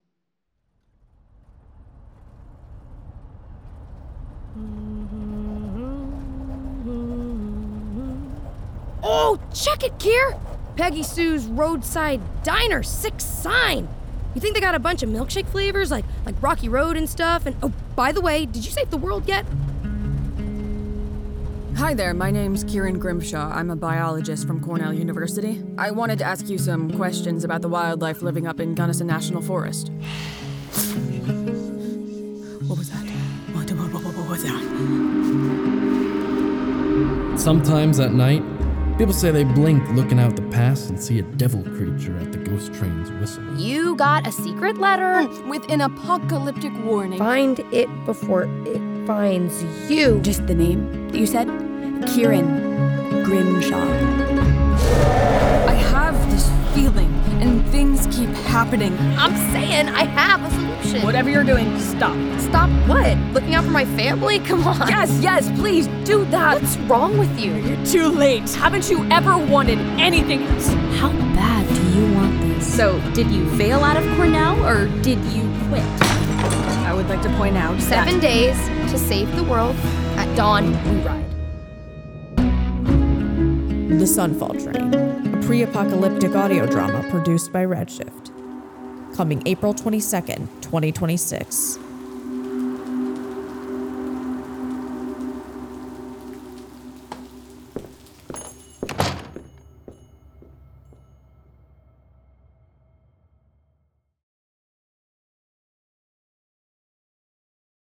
The Sunfall Train is a pre-apocalyptic, queer western by REDSHIFT Audio, coming April 22nd, 2026. Episodes of this 8-part audio drama will be released every other week on Wednesdays.